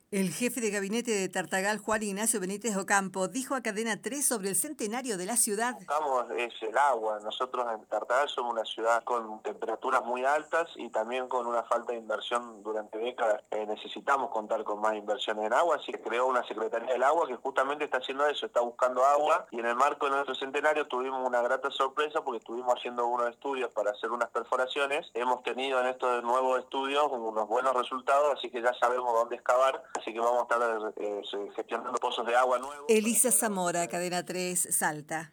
El jefe de gabinete de Tartagal, Juan Ignacio Benítez Ocampo, destacó en diálogo con Cadena 3 los avances en futuros pozos de agua, en medio de la necesidad de más inversión en el suministro para la ciudad.